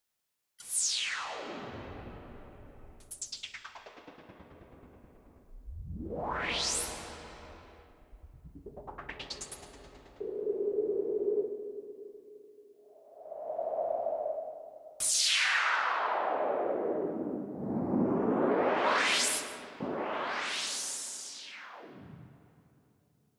Zum Vergrößern anklicken.... hab mal schnell die wesentlichen sounds aneinander gehangen. bei mir fliegt es wieder runter, da ich das im prinzip mit dem rauschen von jedem synth selber basteln kann. Anhänge noise.mp3 noise.mp3 916,3 KB · Aufrufe: 239